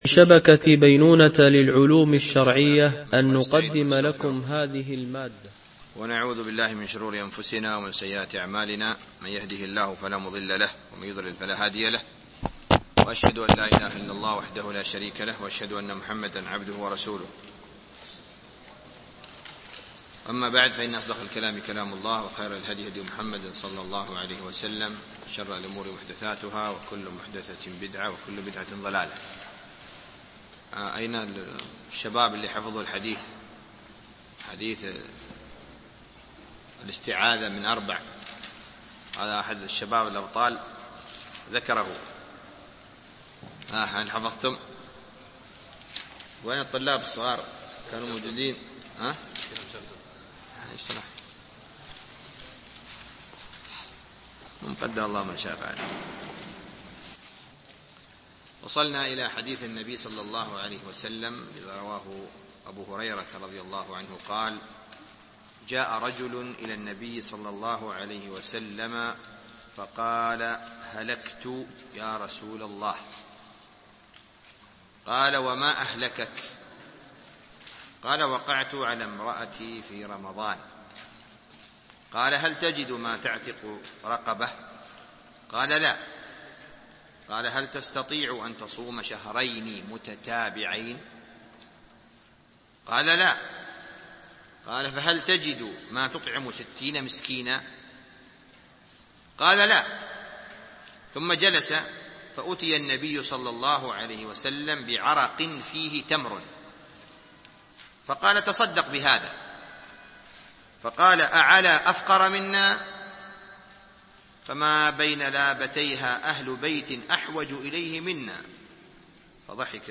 شرح كتاب الصيام من بلوغ المرام ـ الدرس الخامس